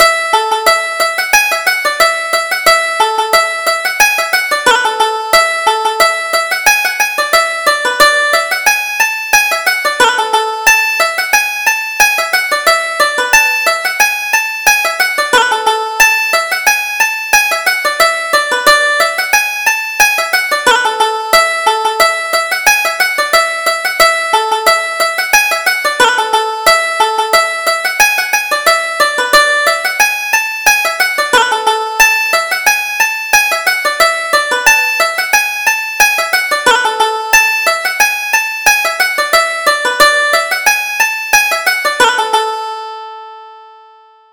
Reel: Dandy Denny Cronin